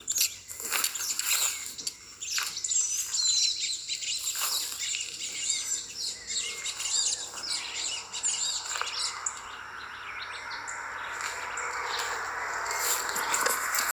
Frutero Overo (Cissopis leverianus)
Nombre en inglés: Magpie Tanager
Localidad o área protegida: Parque Provincial Urugua-í
Condición: Silvestre
Certeza: Fotografiada, Vocalización Grabada
Frutero-overo_1.mp3